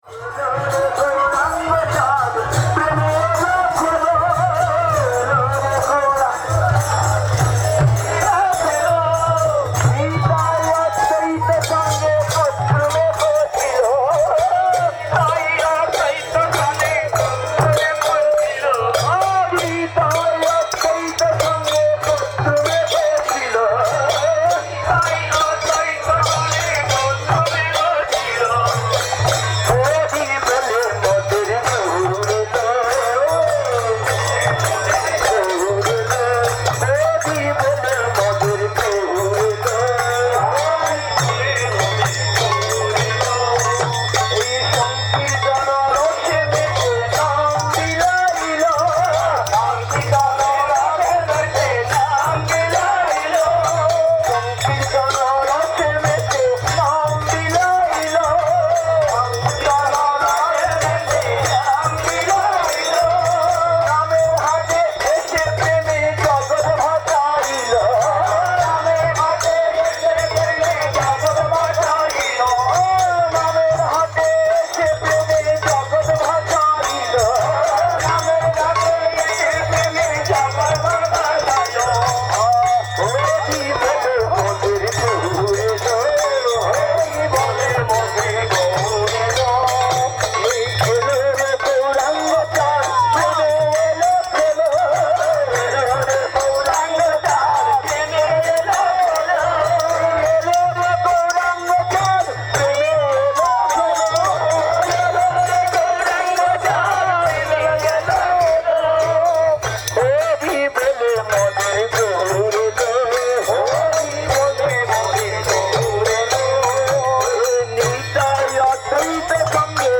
Gaura Purnima Parikrama 2012
Place: SCSMath Nabadwip
Kirttan